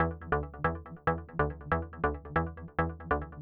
VTS1 Selection Kit Bassline